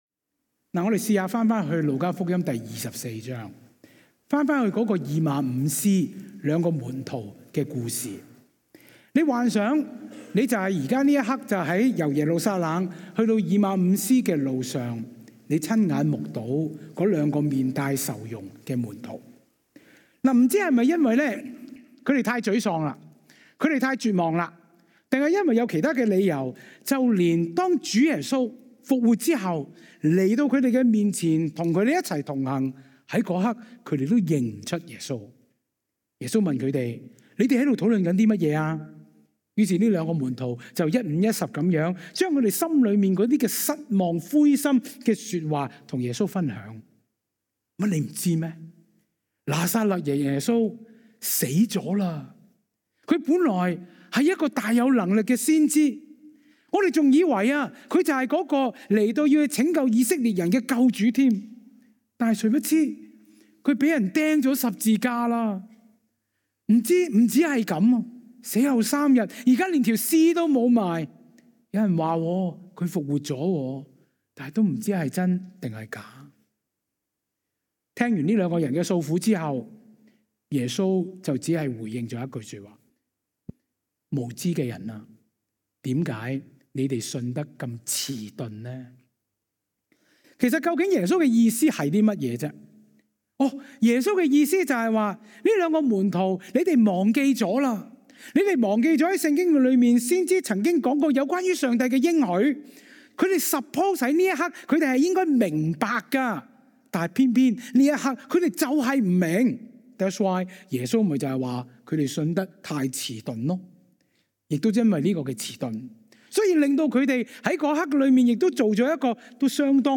下載 下載 歌鄰基督教會 本週報告事項 (Nov 29, 2025) 鈍 Current 講道 鈍 (3) 唔返喇！